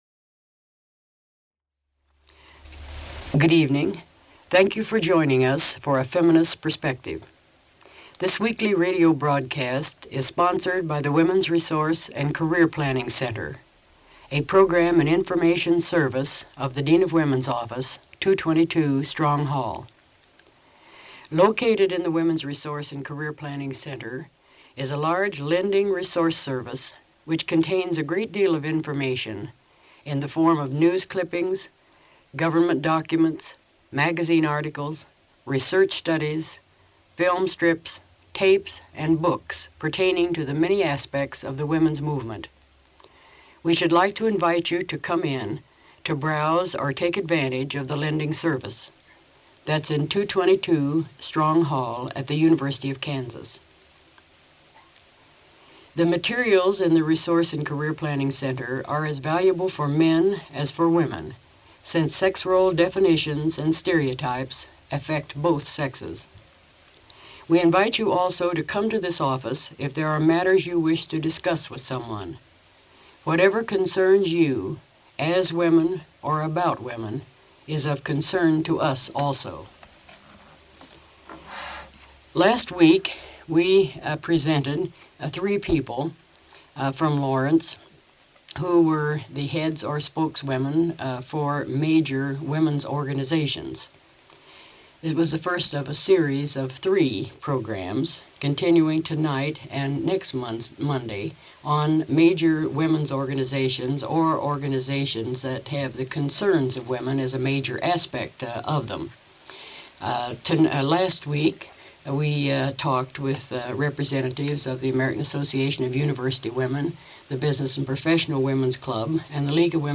Feminism and higher education Women's rights Women college students Radio talk shows